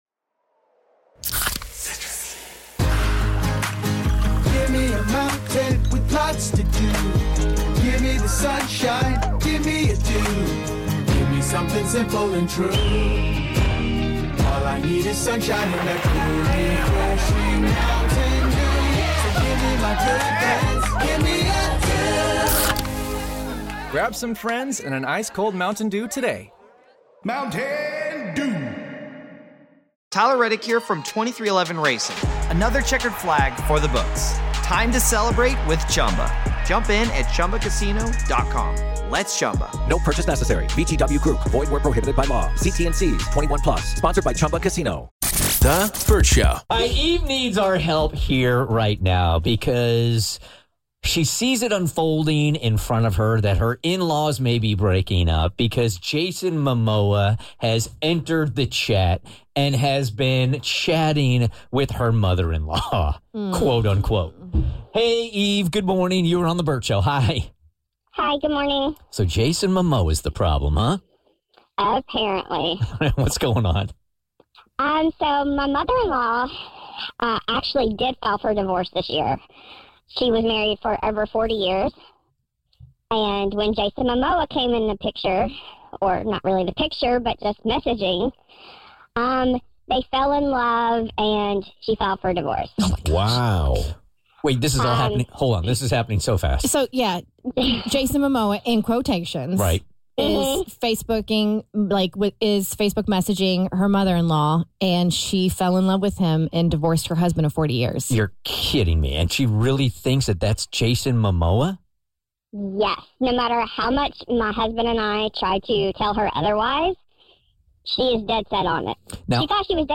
She called in with a major challenge for us…she needs our help stopping a scammer!